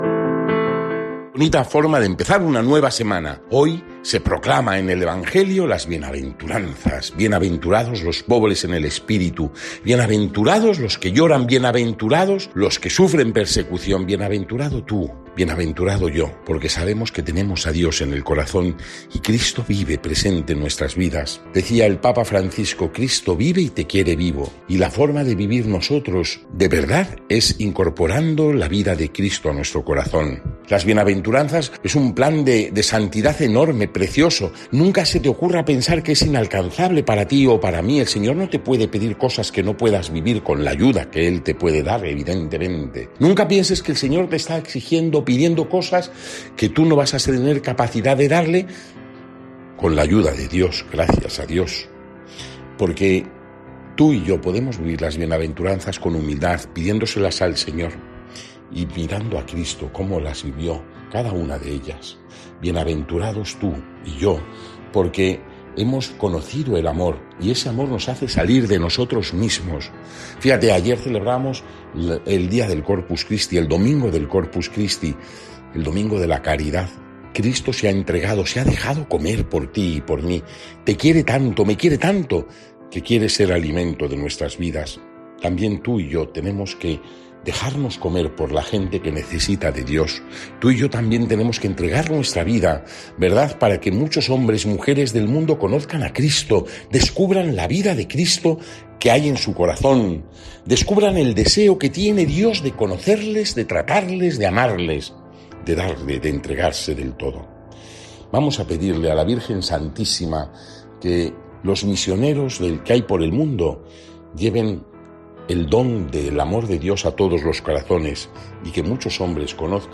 Evangelio según san Mateo (5, 1-12) y comentario